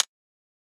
UHH_ElectroHatC_Hit-15.wav